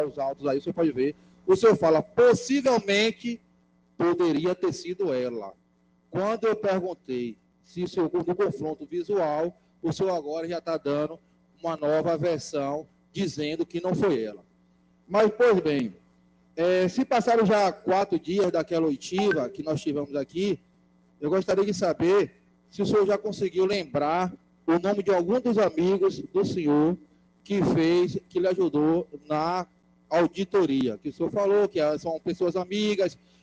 O secretário foi desmoralizado no plenário da Câmara ao mentir no depoimento da CPI nesta terça-feira (12).
Ouçam os áudios no momento em que Dr. Marcelo teria mentido na CPI e foi duramente advertido pelos edis.